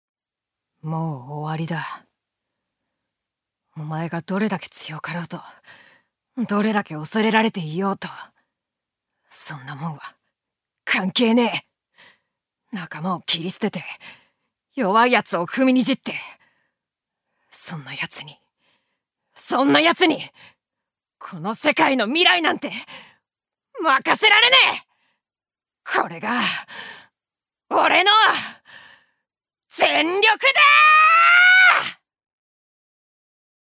ジュニア：女性
セリフ４